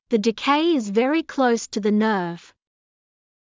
ｻﾞ ﾃﾞｨｹｲ ｲｽﾞ ｳﾞｪﾘｰ ｸﾛｰｽ ﾄｩ ｻﾞ ﾅｰｳﾞ